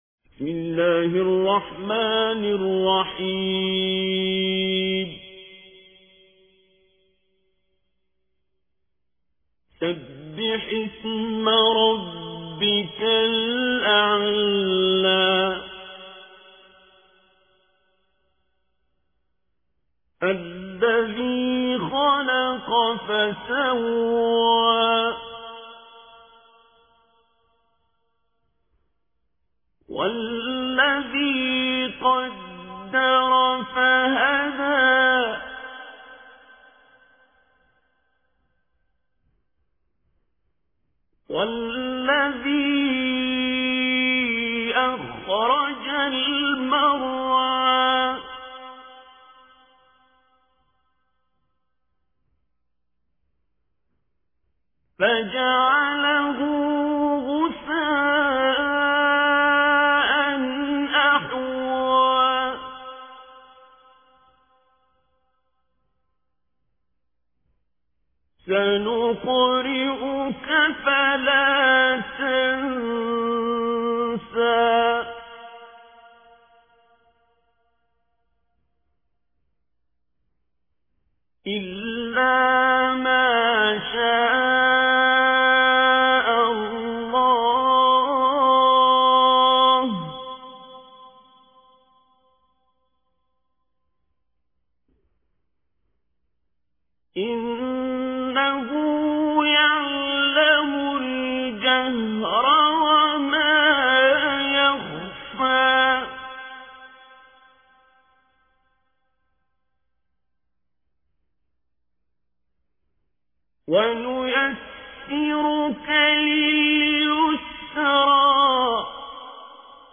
دانلود صوتی سوره اعلی با صدای عبدالباسط